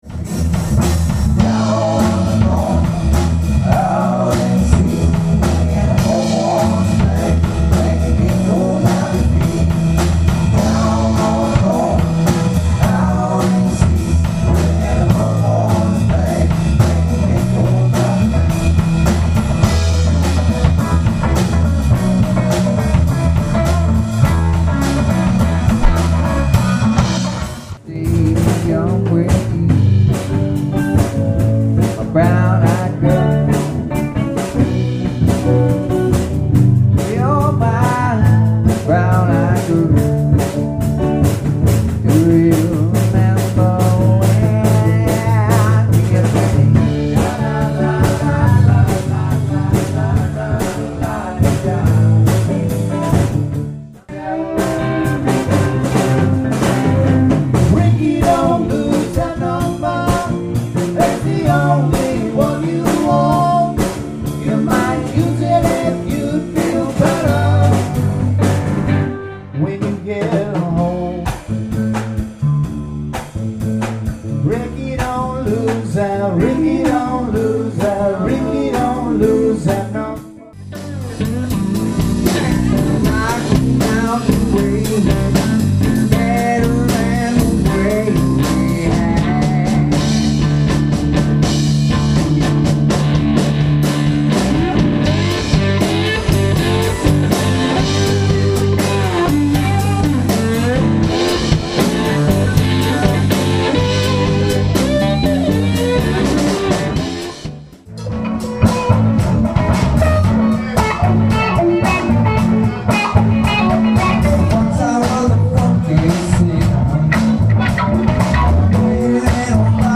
Rock Cover Trio “The Way”
– 2015 bar gig set 1: play